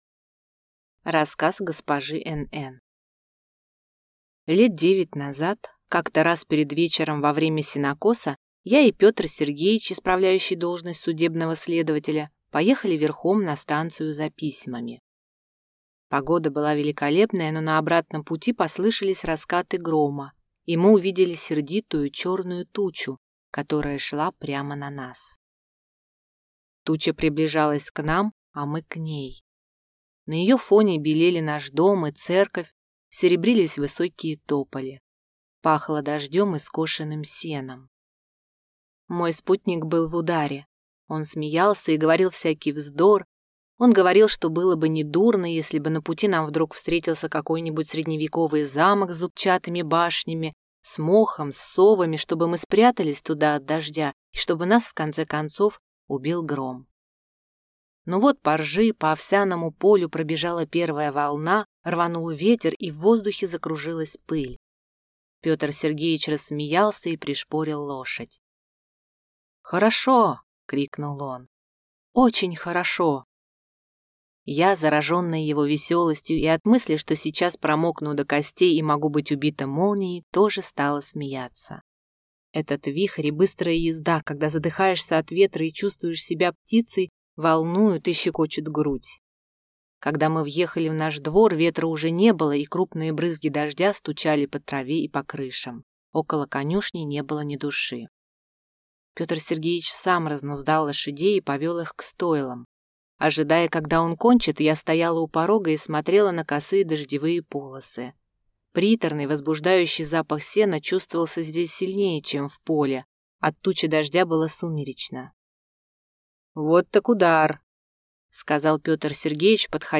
Аудиокнига Рассказ госпожи NN | Библиотека аудиокниг